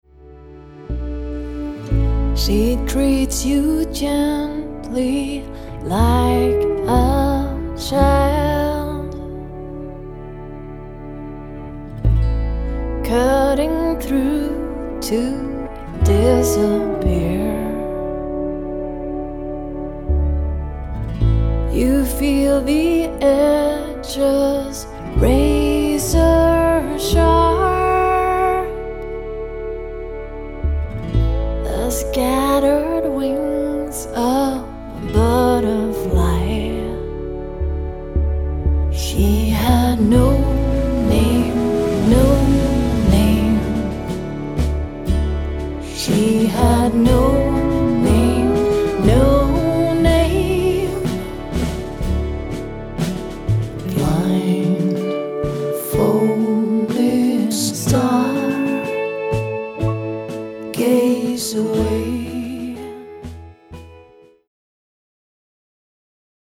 Vakkert og direkte.
tangenter
gitarer
el.bass
trommer